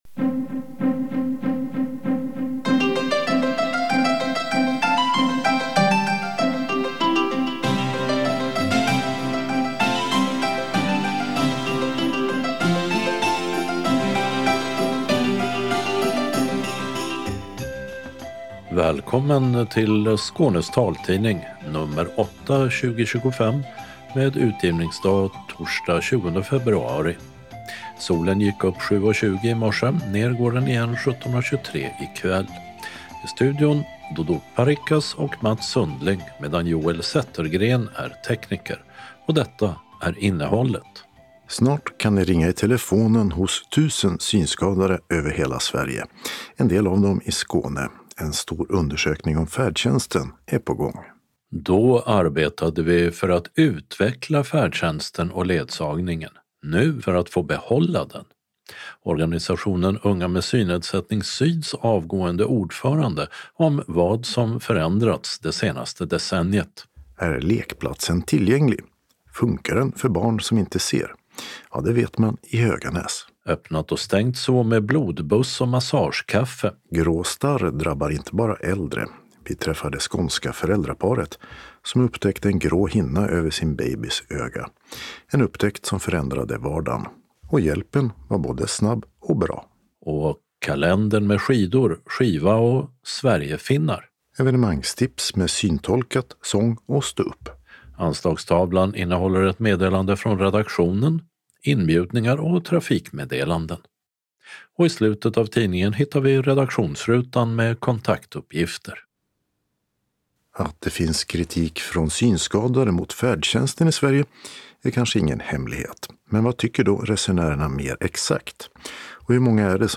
Taltidning